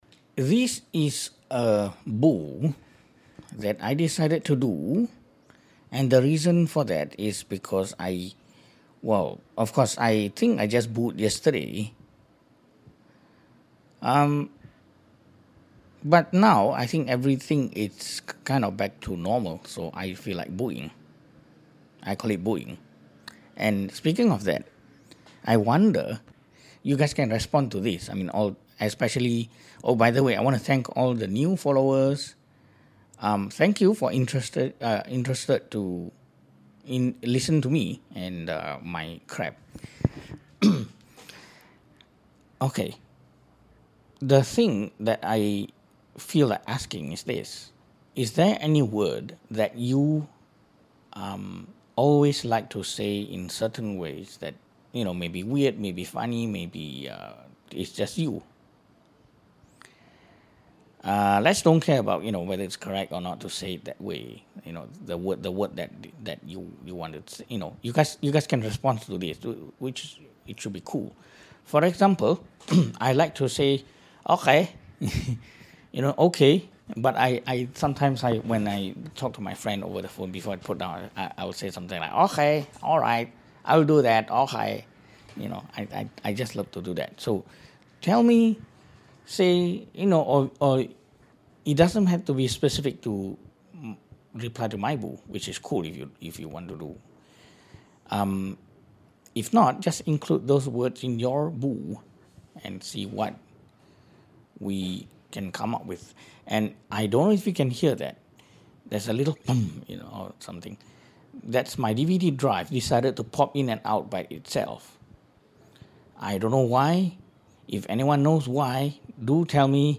few questions. any word that you love to say it in some funny ways? dvd drive popping in and out. hmm. why.